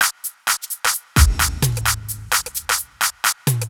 Downtown House/Loops/Drum Loops 130bpm